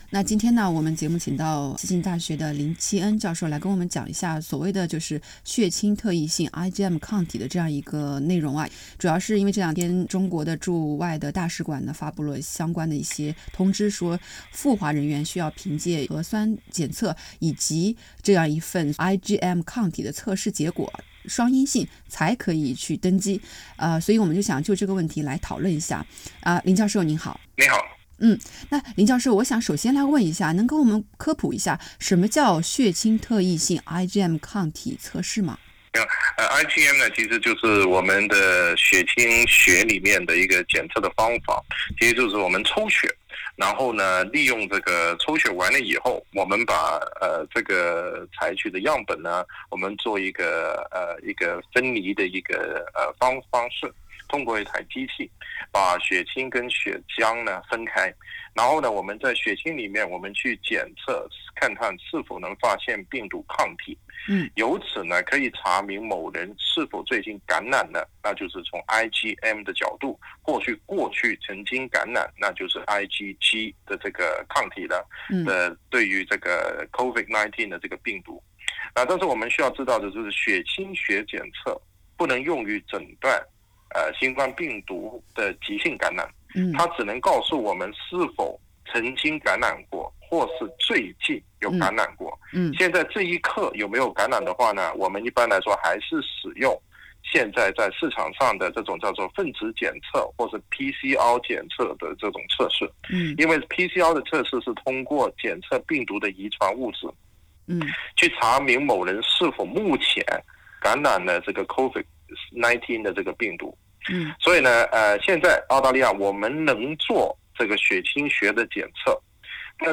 收聽完整寀訪）。 從11月8日起，自澳大利亞出髮前往中國的人士也需憑雙陰性證明才可乘機。